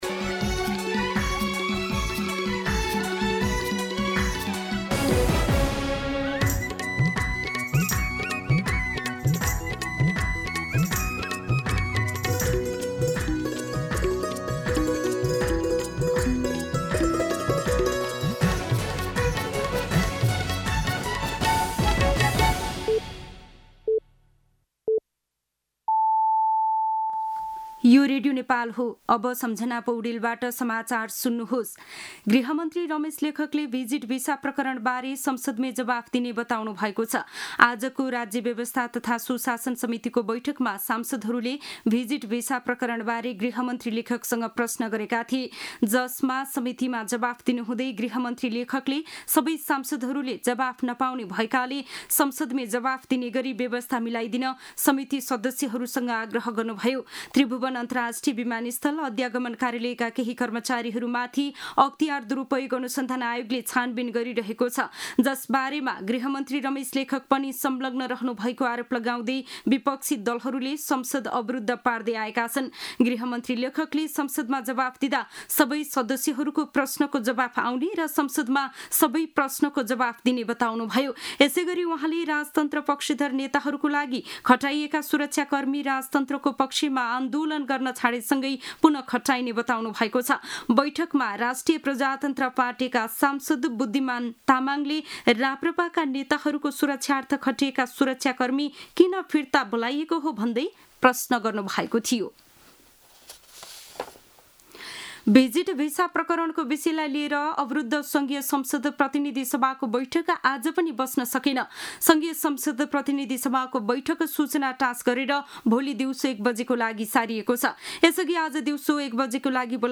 दिउँसो ४ बजेको नेपाली समाचार : २१ जेठ , २०८२